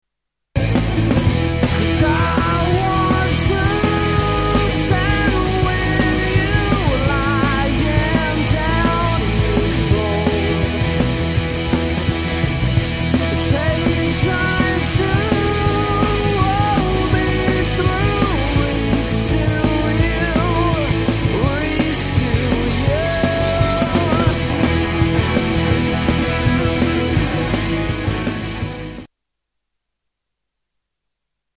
Devon-based indie rockers
Style: Rock